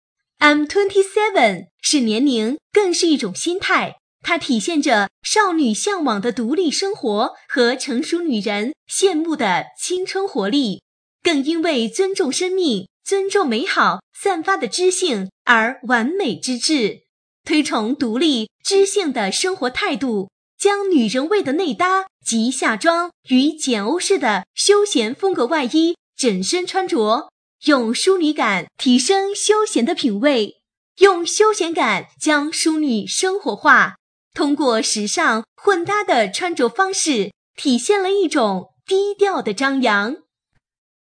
【女23号介绍】IAM27（我27